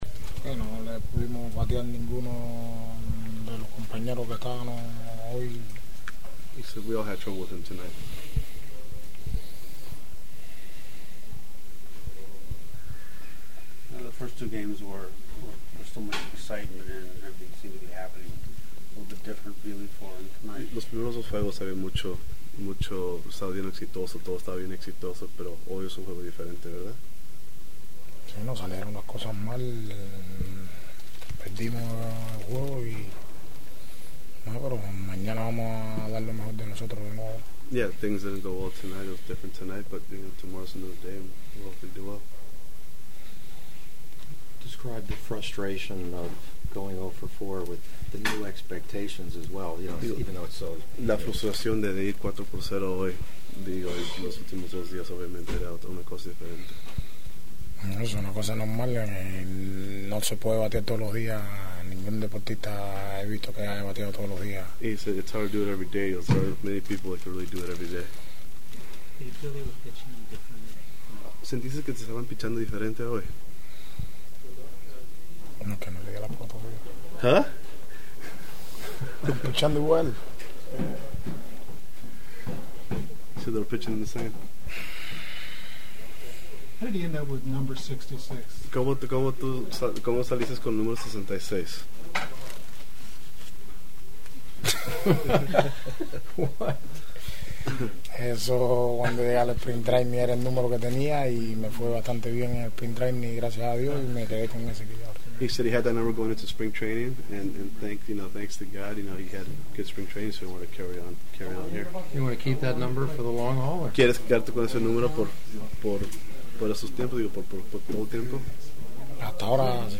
It’s the first time in many moons that I recorded a player’s Spanish speaking interview but I thought you might like to hear his tone of voice and words (in English from his interpreter) including when we might expect to get an English speaking chat from him?
The following are my sounds of the postgame…
Dodger RF Yasiel Puig in Spanish (with his English interpreter):